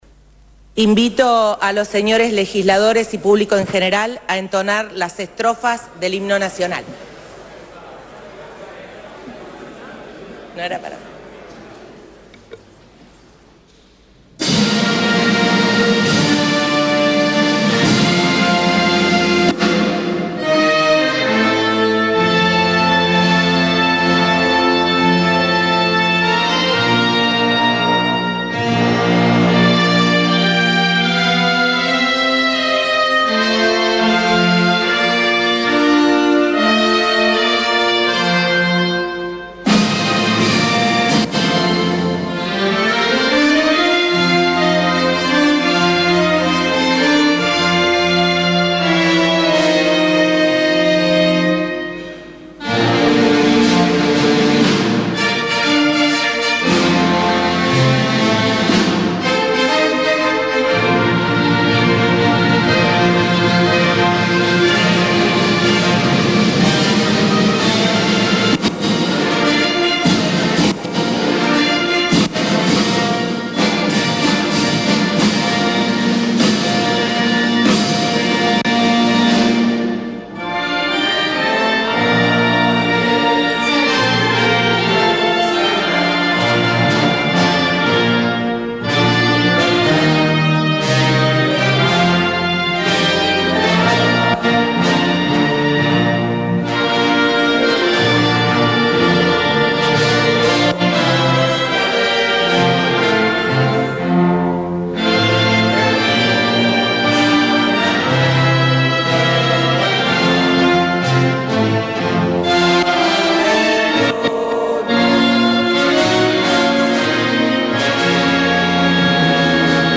Lo dijo este mediodía en el acto protocolar ante la Asamblea Legislativa.
PULLARO-DISCURSO.mp3